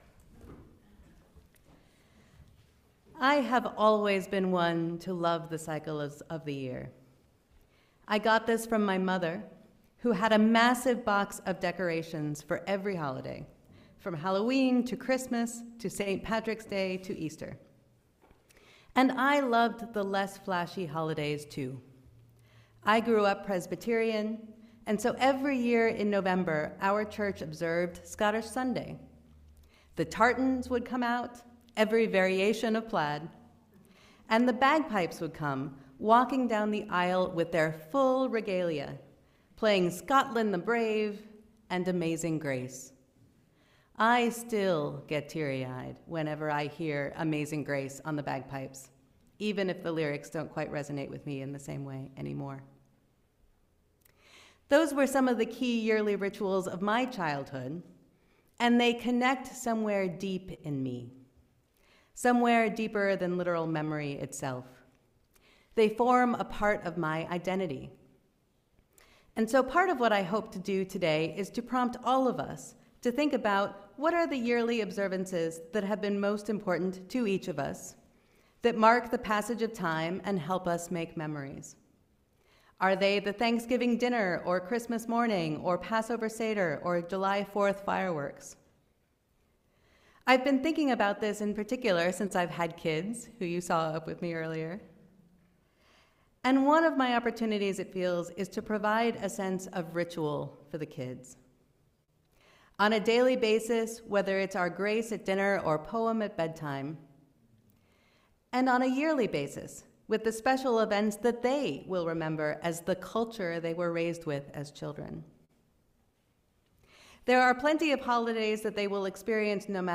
May Day is a holiday celebrating the vibrancy and creativity of spring, as well as a day of worker recognition and protest. In this service we will seek joy and purpose in each of the rituals of our lives together… and all are welcome at the maypole afterwards for ribbons, music, and dancing!